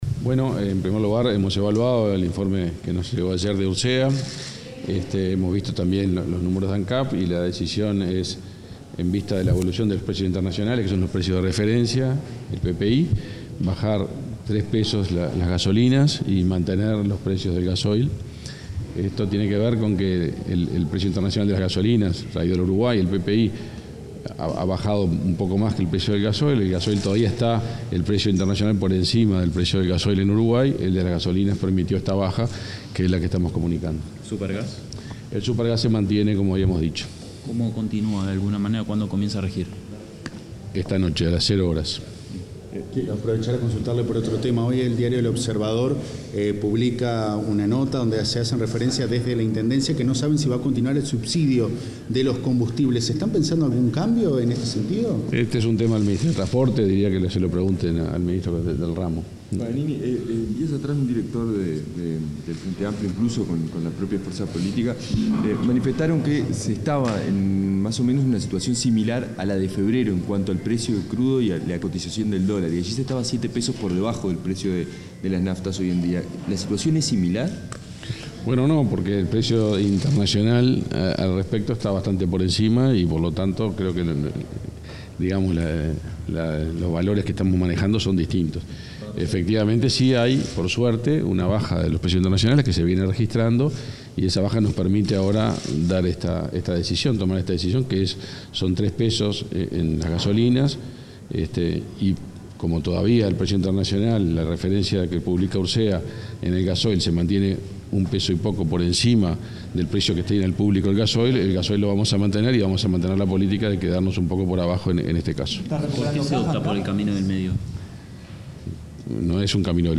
Declaraciones del ministro de Industria, Omar Paganini
El ministro de Industria, Omar Paganini, informó a la prensa sobre el ajuste de combustibles determinado por el Gobierno a partir del 1.° de setiembre